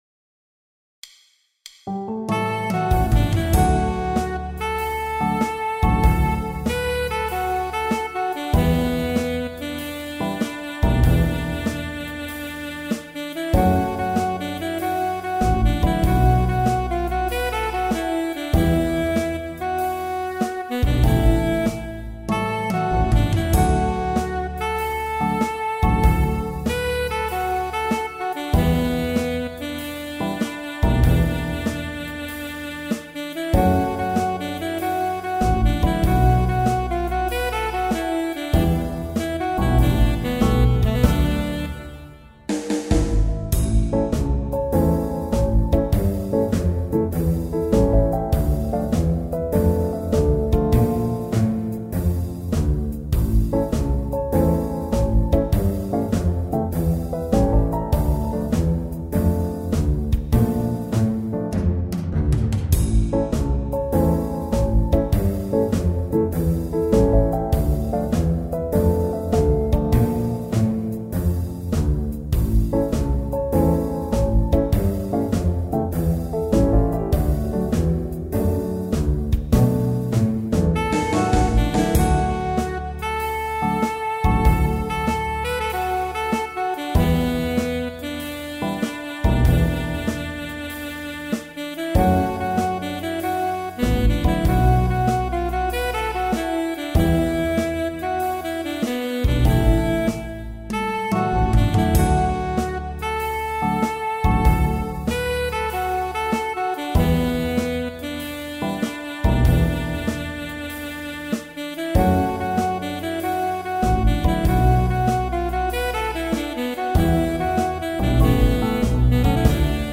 ジャズスローテンポロング